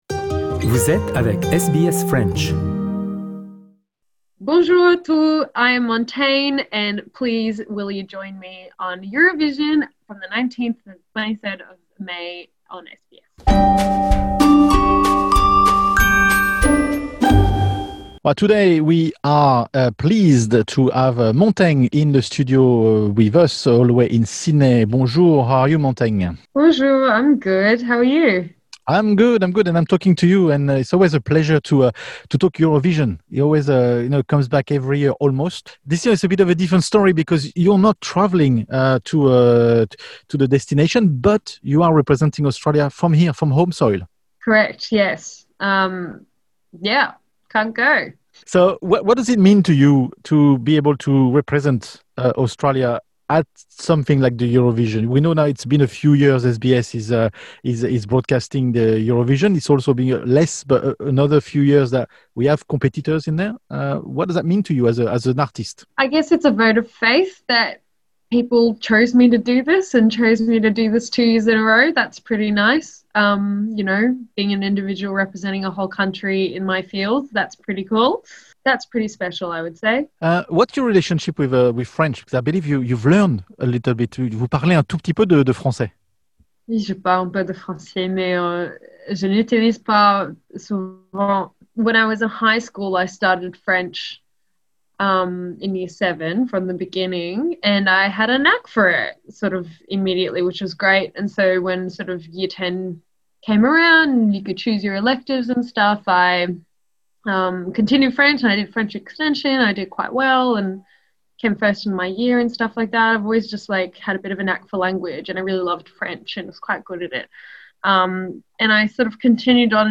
Rencontre avec Montaigne, elle représente l'Australie a l'Eurovision 2021